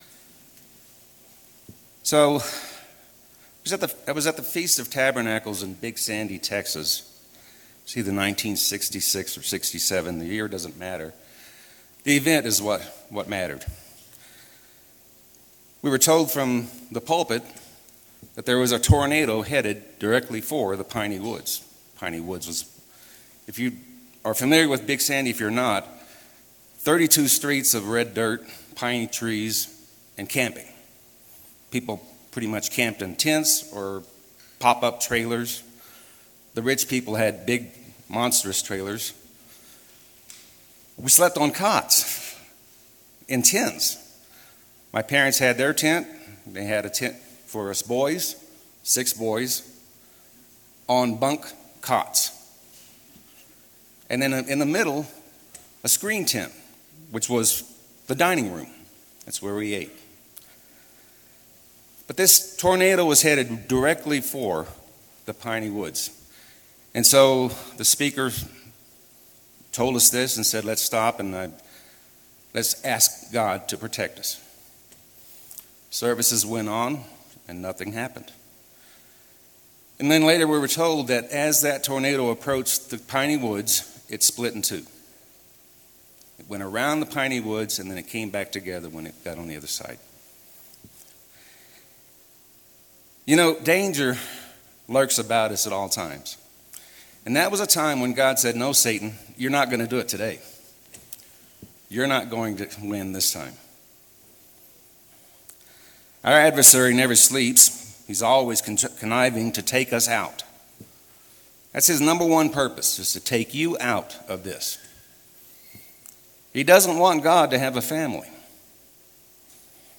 This sermon was given at the Estes Park, Colorado 2023 Feast site.